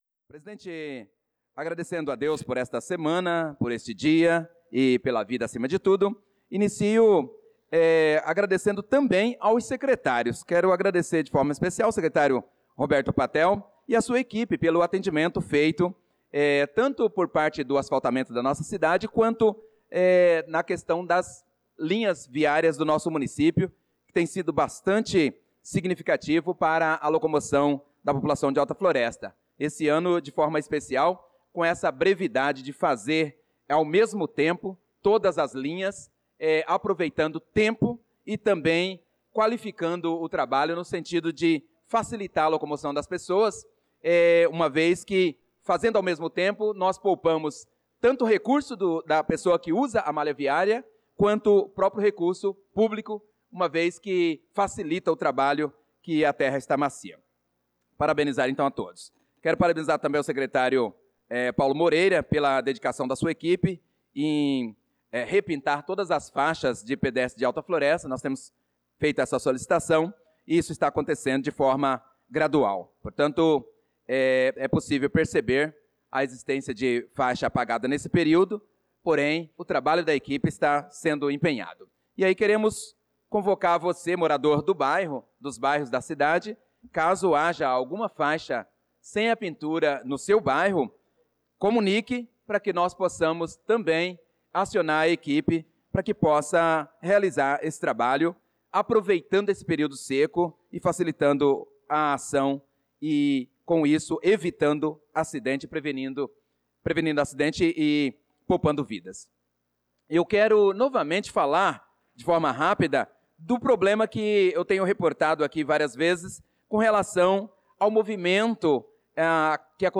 Pronunciamento do vereador Prof. Nilson na Sessão Ordinária do dia 16/06/2025.